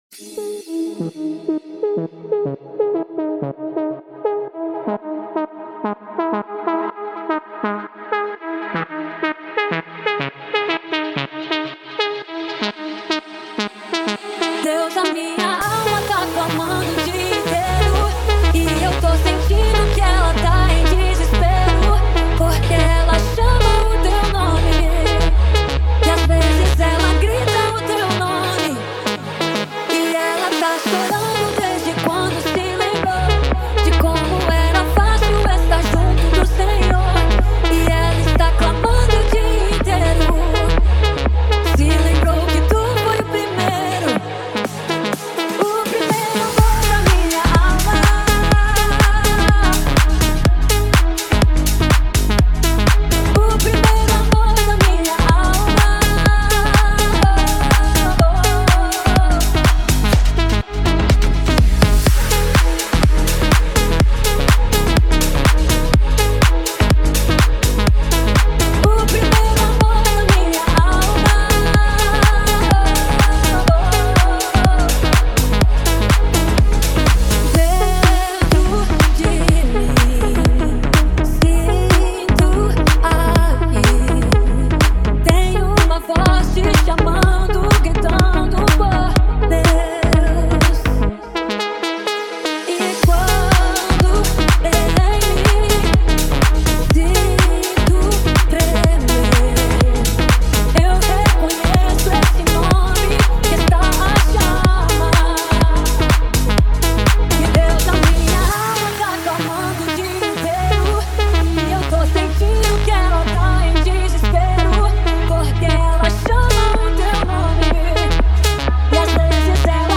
Gênero Gospel.